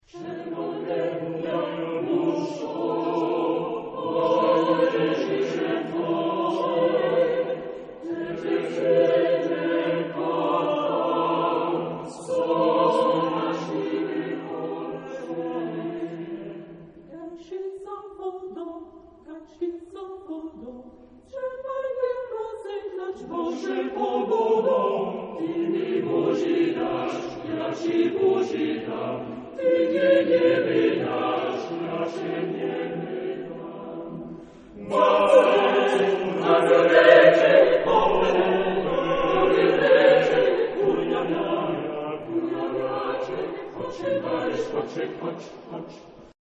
Genre-Style-Forme : Chanson ; Folklore ; Profane
Caractère de la pièce : joyeux ; dansant
Type de choeur : SATB  (4 voix mixtes )
Tonalité : mi mineur